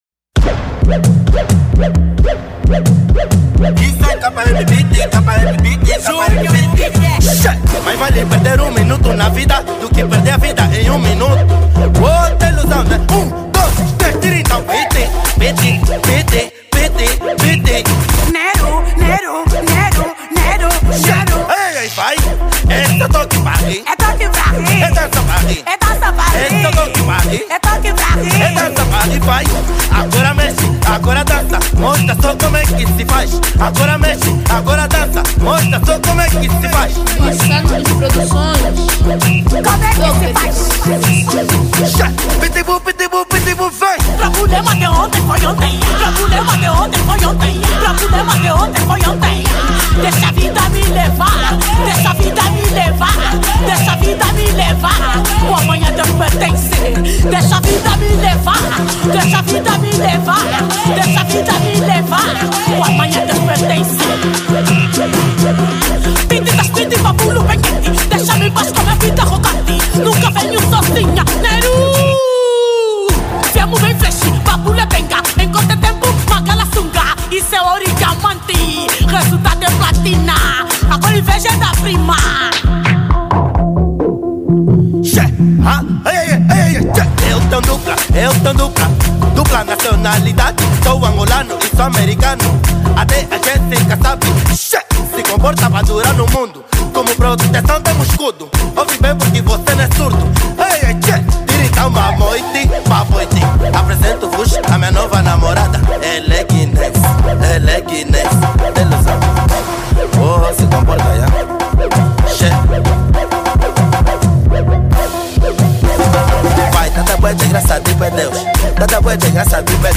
Categoria    Afro House